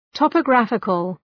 Προφορά
{,tɒpə’græfıkəl}